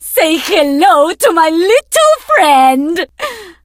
diva_ulti_vo_01.ogg